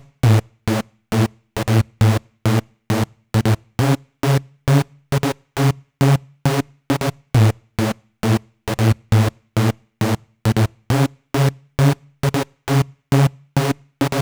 TI CK7 135  Detune Saw 1.wav